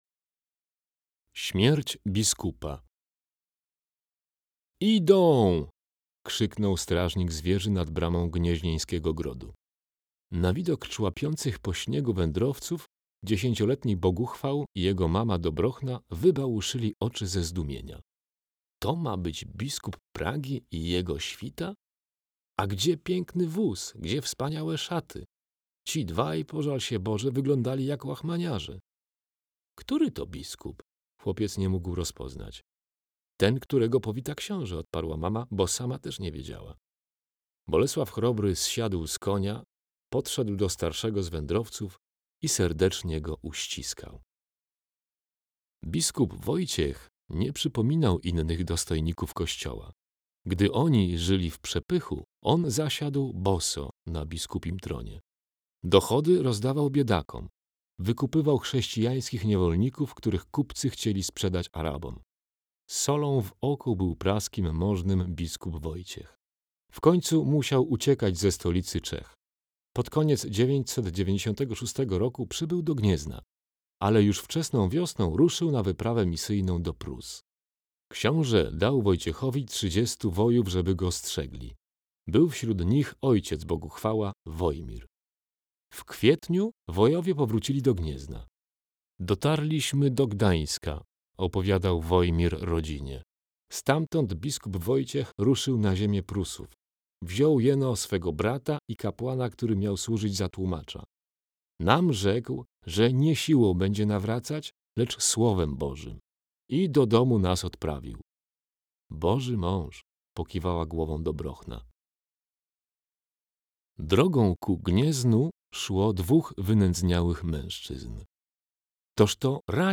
Słuchowiska. Klasa 5
Opowiadania 13–14 – cykl słuchowisk ukazujący państwo polskie w czasach panowania Bolesława Chrobrego